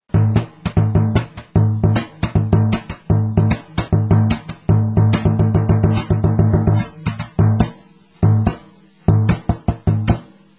סורדו - תוף הבס
.תוף רחב וגדול המפיק צלילים עמוקים ועמומים
.בסורדו מנגנים בעזרת מקלות מרופדים
surdo.wma